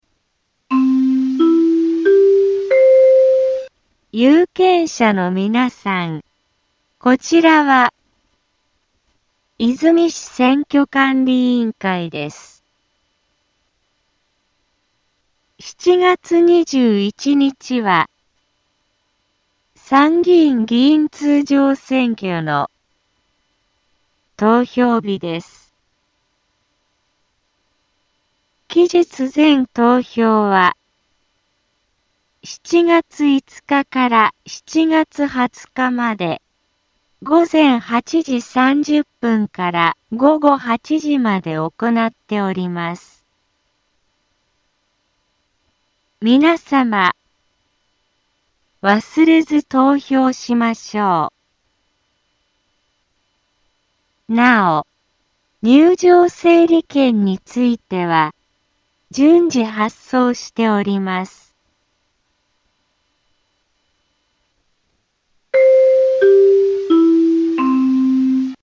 Back Home 災害情報 音声放送 再生 災害情報 カテゴリ：通常放送 住所：大阪府和泉市府中町２丁目７−５ インフォメーション：有権者のみなさん こちらは、和泉市選挙管理委員会です。 7月21日は、参議院議員通常選挙の、投票日です。